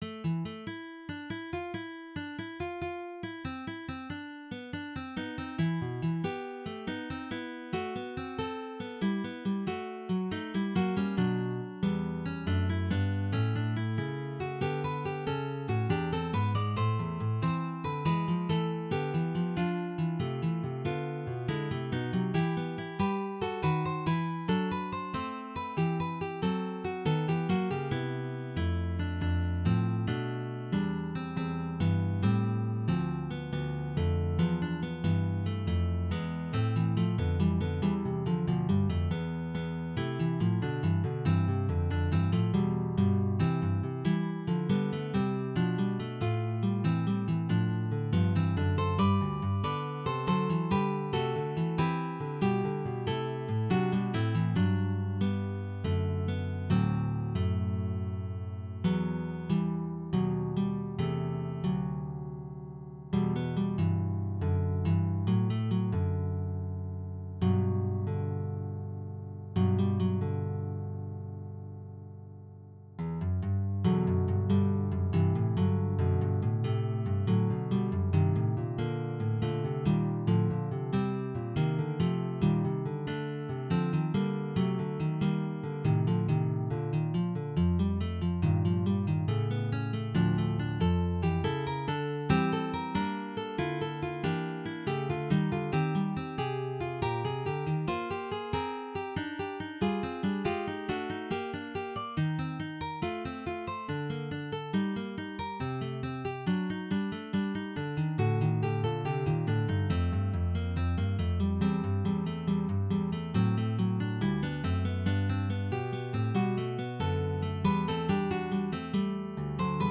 New tin whistle fugue
It’s one of only two fugues I’ve written so far for tin whistle duet, the other of which can be found here.